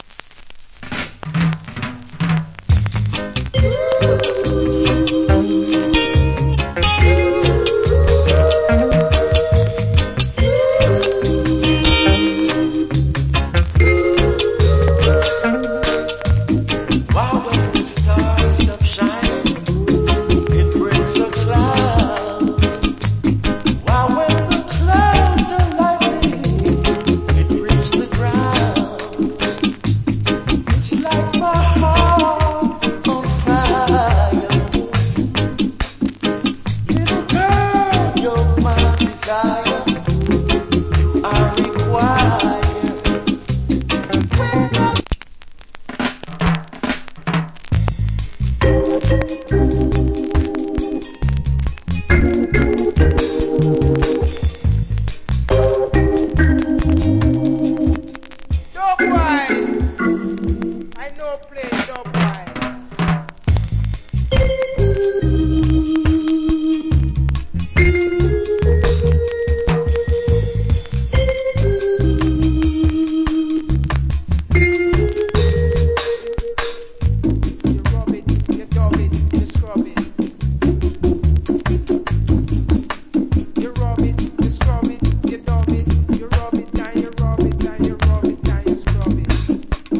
Old Hits. Great Reggae Vocal.